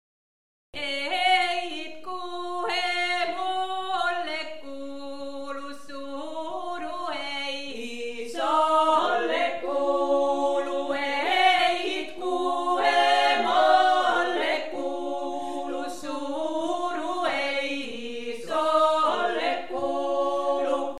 Hoz mie itken (Kuhmon Kalevalakylä 2004). Laulaja odottaa, että pääsee itkemään ja laulamaan tuntonsa ulos marjametsässä.